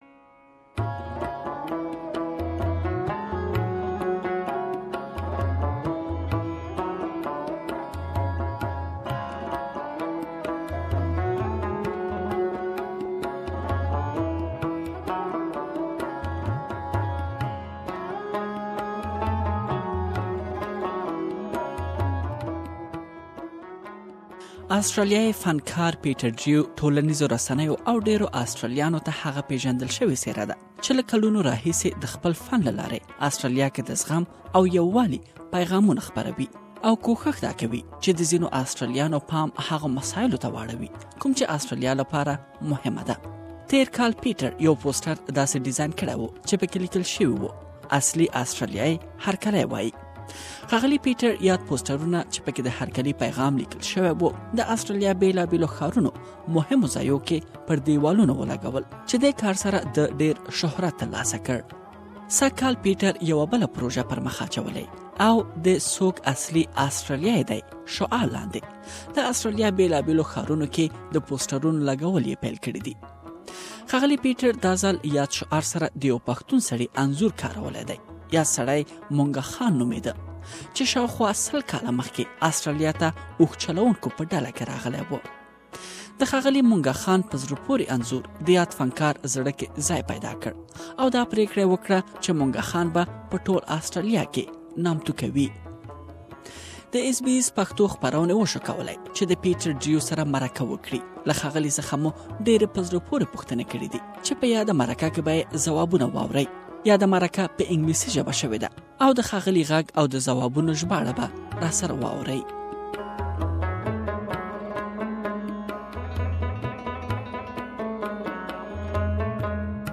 په زړه پورې مرکه مو ورسره کړې چې دلتي يې بشپړه مرکه اوريدلی شئ